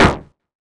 golpe_bang.wav